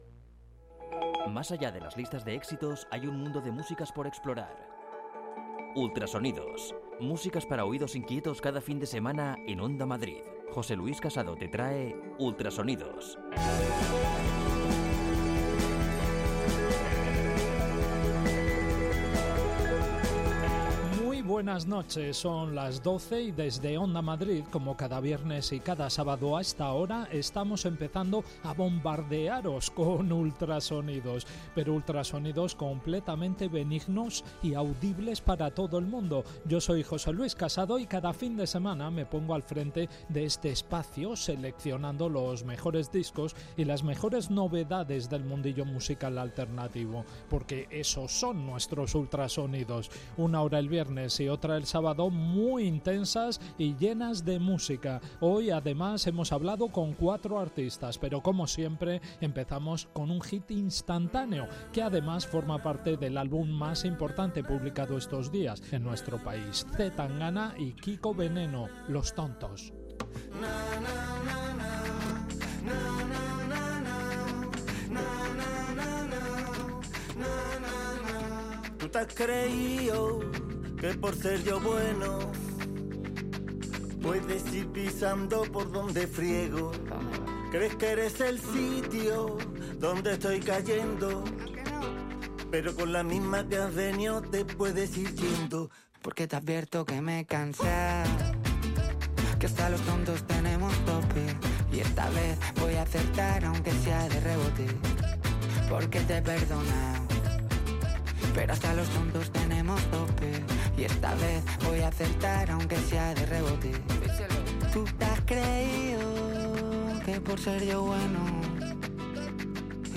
Un viaje por la música nacional y la internacional con espíritu crítico, enfoque divulgativo y un toque de humor.